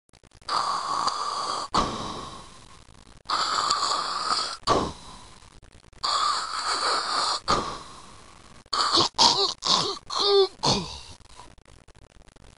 De klank was ronduit beangstigend.
Darth Vader die een astma aanval krijgt nadat hij een hele sloef Johnson sigaretten zonder filter heeft opgepaft.
DarthVaderMetAstmaNaSloefJohnson.wma